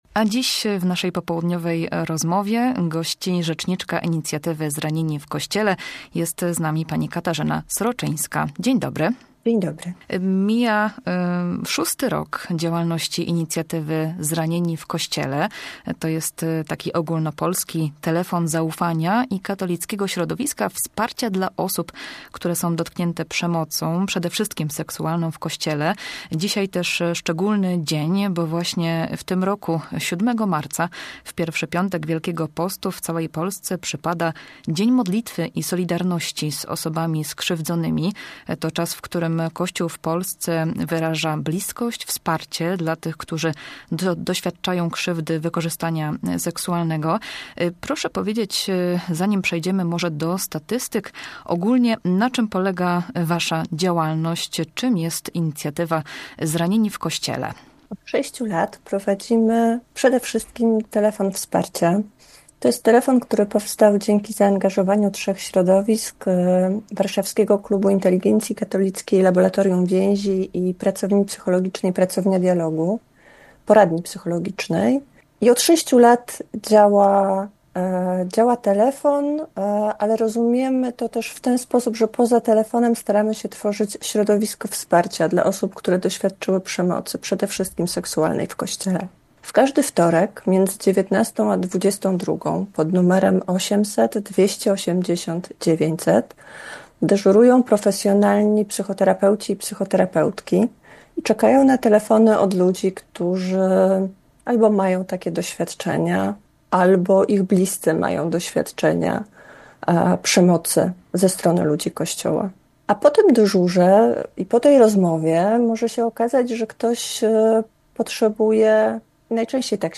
Z jakimi problemami najczęściej dzwonią osoby skrzywdzone – o tym w Popołudniowej rozmowie Radia Poznań mówiła